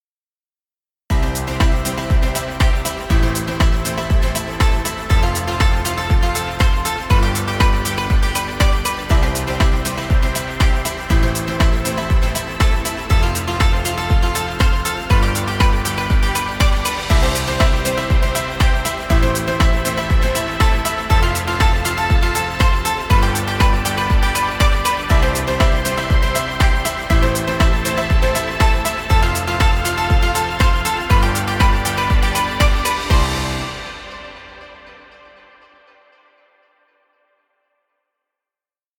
Fun happy motivational music. Background music Royalty Free.